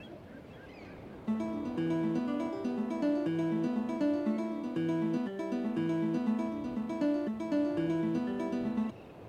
Lute
Plays a tune on the lute for 8 seconds.